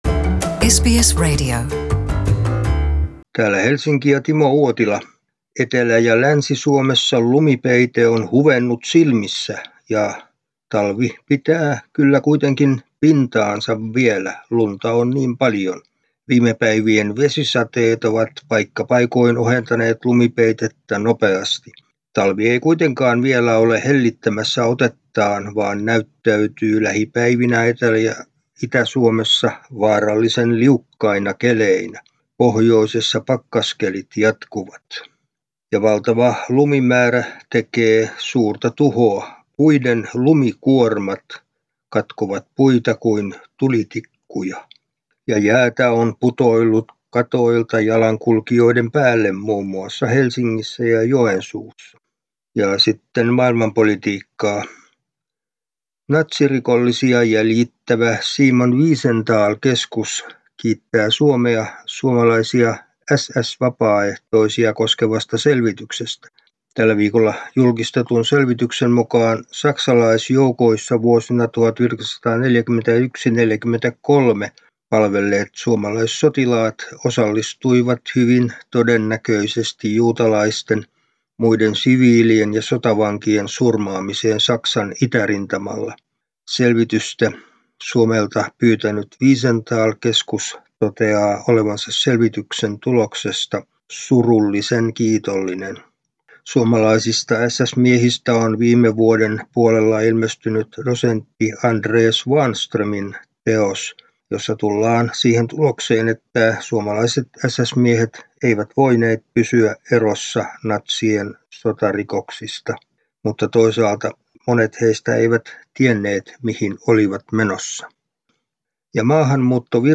Suomen ajankohtaisraportti